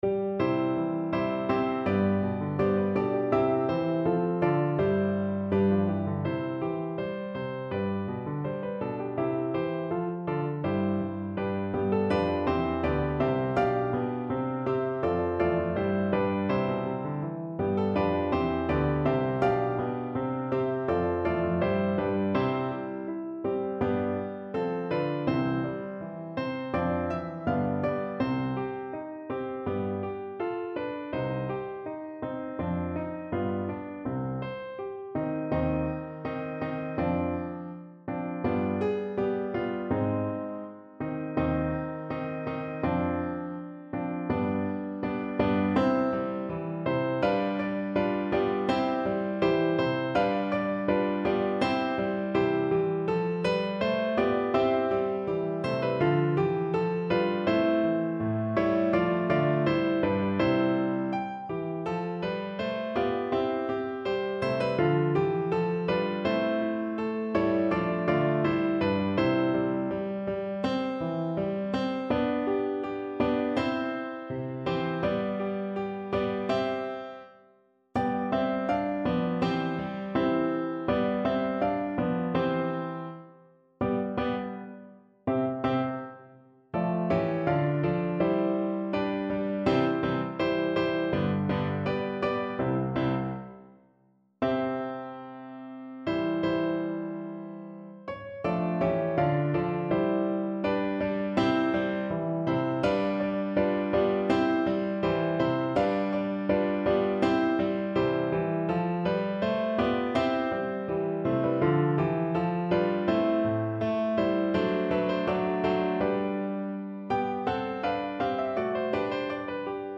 Notensatz (4 Stimmen gemischt)
Musik: Franz Joseph Haydn (*1732 †1809)